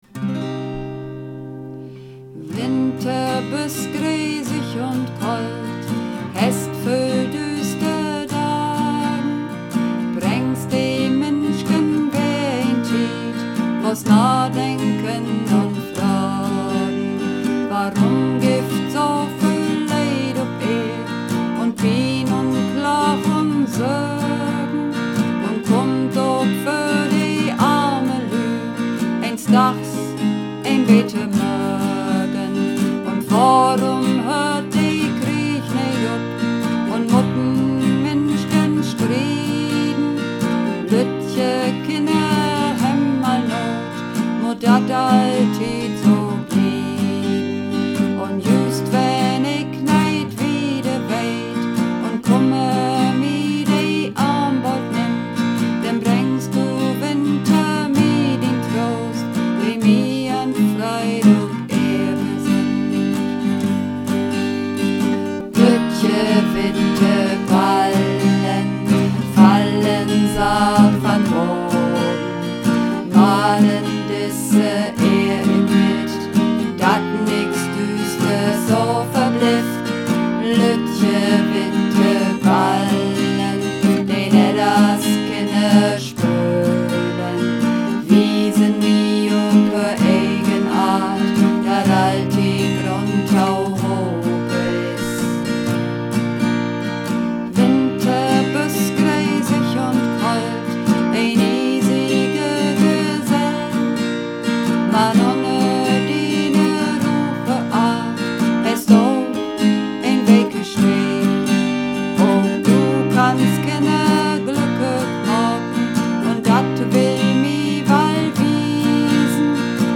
Übungsaufnahmen - Lüttje witte Ballen
Runterladen (Mit rechter Maustaste anklicken, Menübefehl auswählen)   Lüttje witte Ballen (Bass)
Luettje_witte_Ballen__2_Bass.mp3